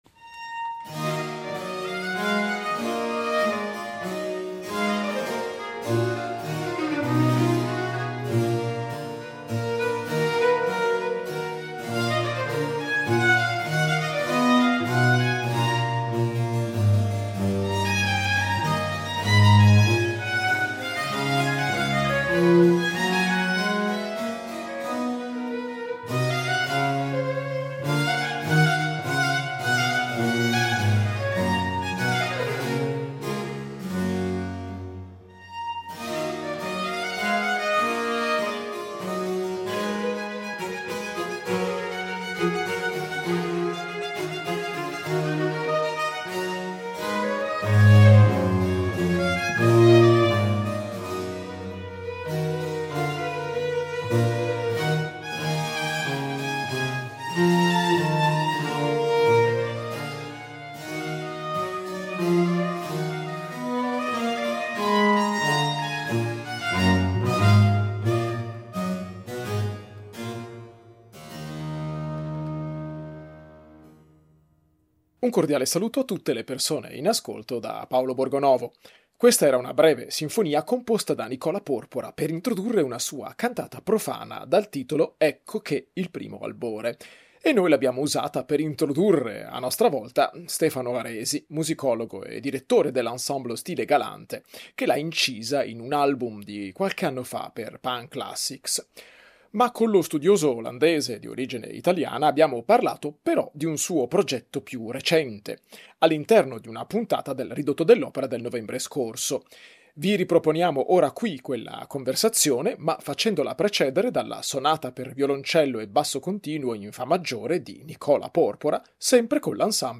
Di questo esperimento abbiamo parlato con il musicologo